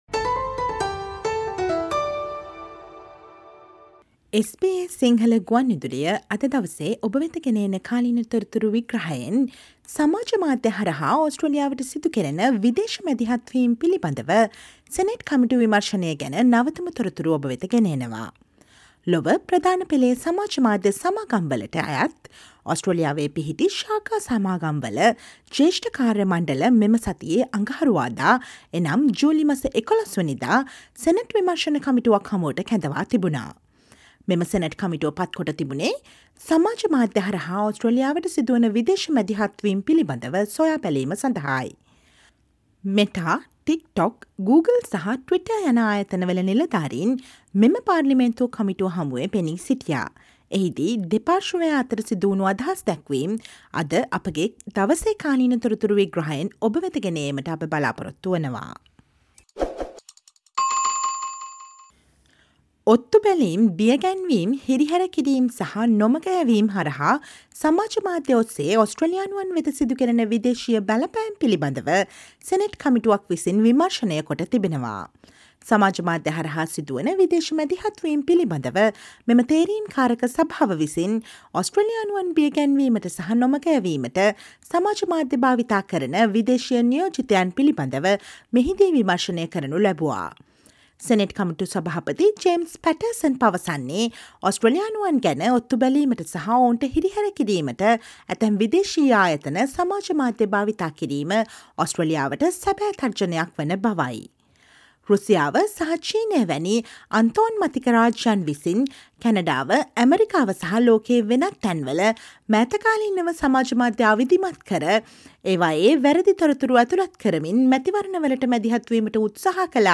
Listen to the SBS Sinhala radio current affair feature on the latest updates of the Senate Committee testimony on threats of foreign interference for Australians through social media platforms.